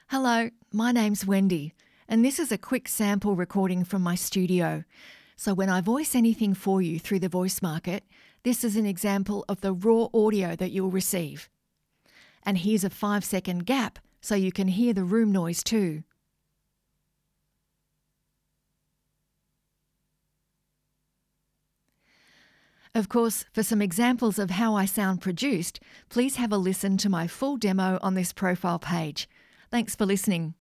• Studio Sound Check
• Rode Procaster mic